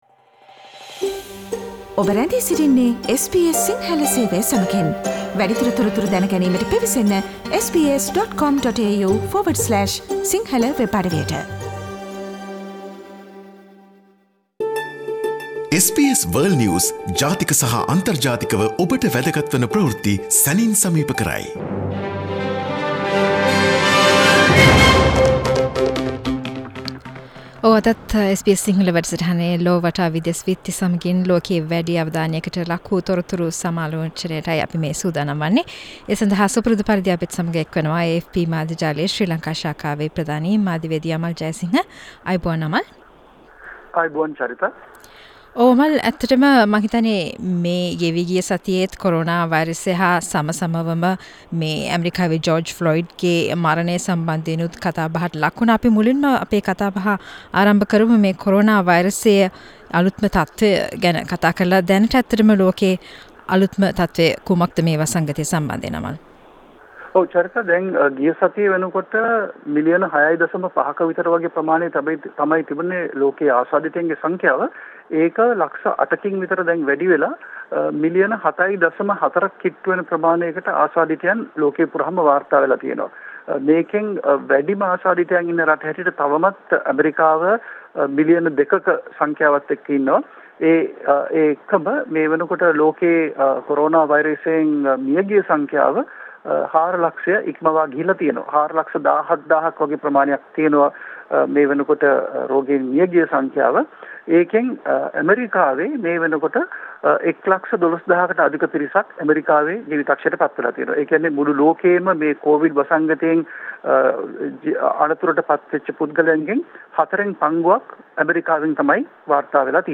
SBS Sinhala world news wrap